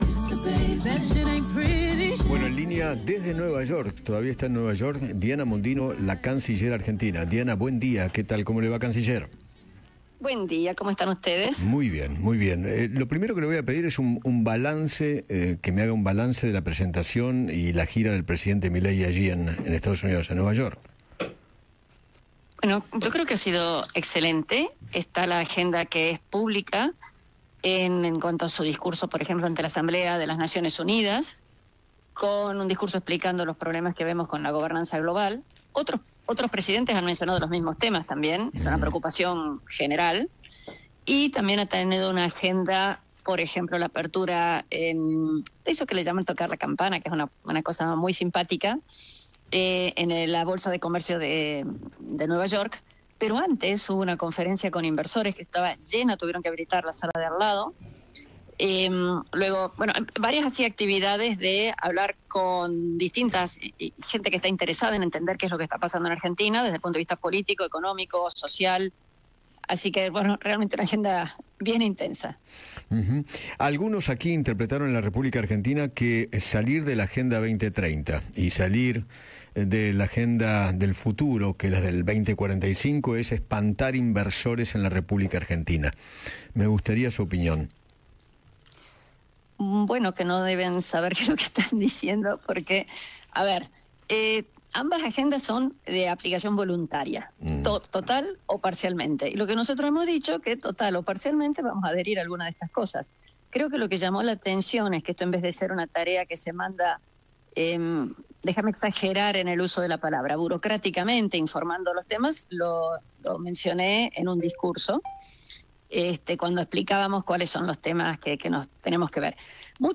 La canciller Diana Mondino dialogó con Eduardo Feinmann sobre la postura argentina frente a la Agenda 2030 y la alineación mundial de la administración de Javier Milei.